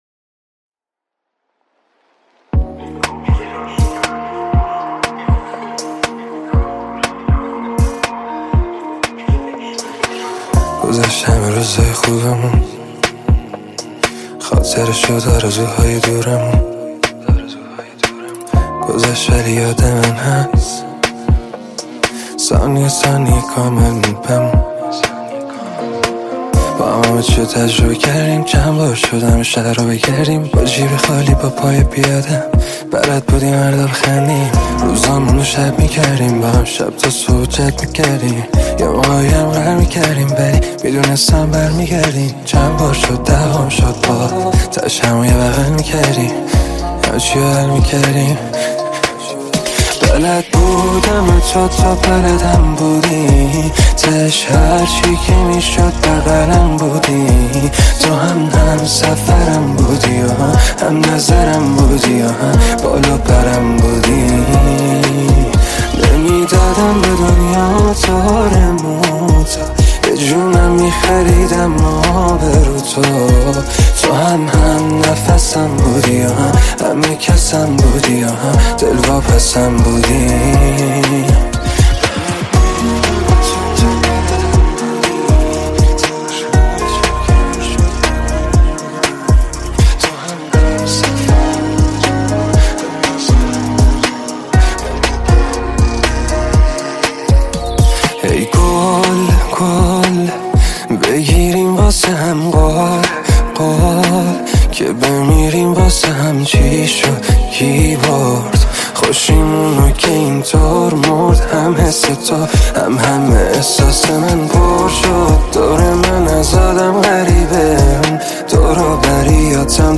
پاپ شاد عاشقانه